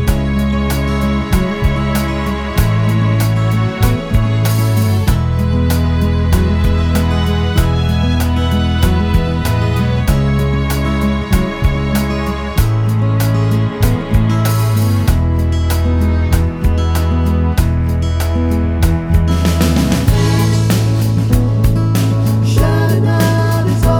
Two Semitones Down Pop (2010s) 3:52 Buy £1.50